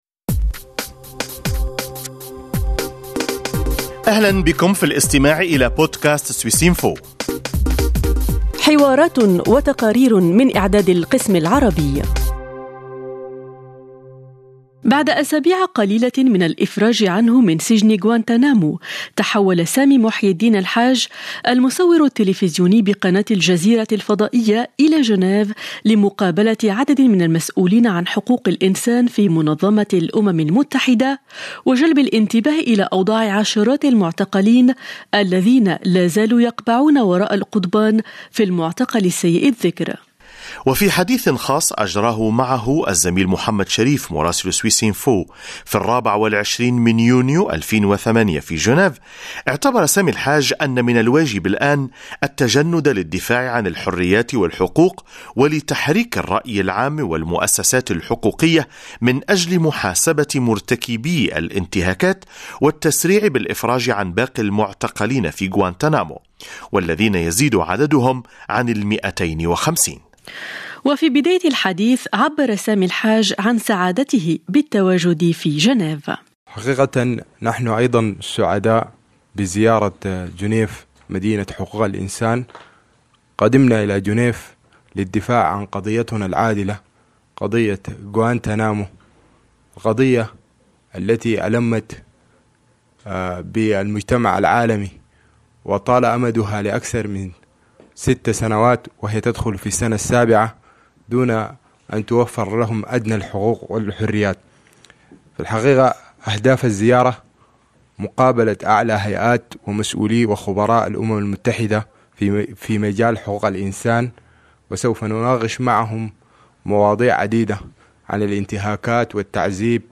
حل مؤخرا سامي محيي الدين الحاج، المصور التلفزيوني بقناة الجزيرة الفضائية، بجنيف حيث روى لسويس انفو شهاداته عن ست سنوات من المعاناة القاسية والمهينة في المعتقل السيء الذكر واستعرض أهم محاور نشاطه المستقبلي.